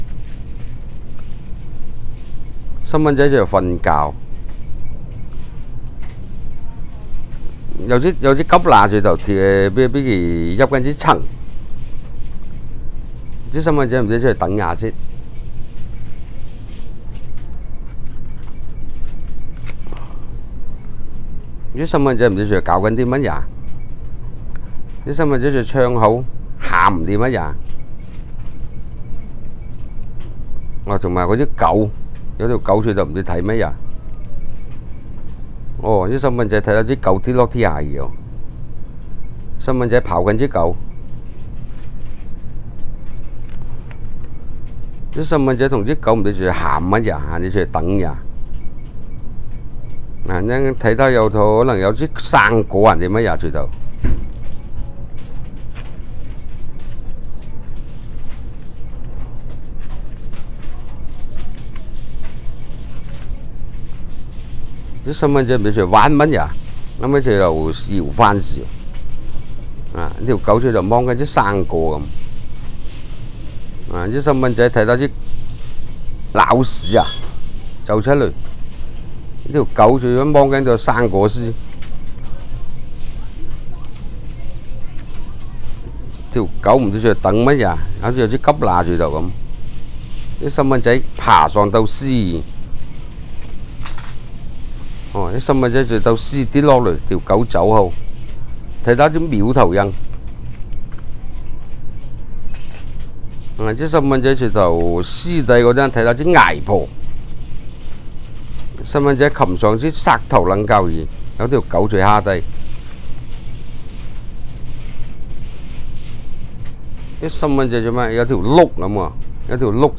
Male, 57
digital wav file recorded at 44.1 kHz/16 bit on Zoom H2 solid state recorder
Dapeng dialect in Shenzhen, China